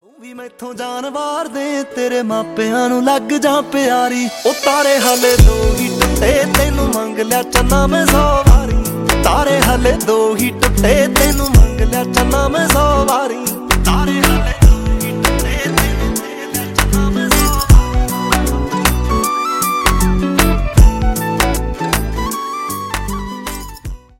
Punjabi sang